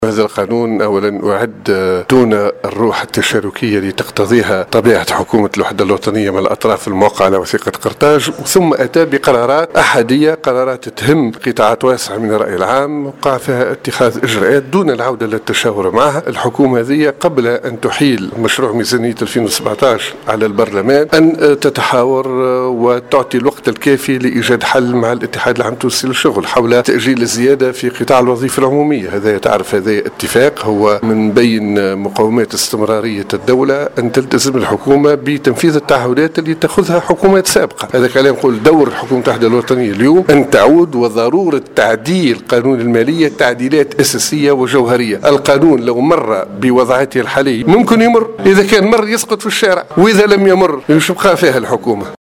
بحسب تصريحاته على هامش اشرافه اليوم على ندوة بصفاقس حول قانون المالية الاشكالات والمقترحات اين التقاه مراسلنا في الجهة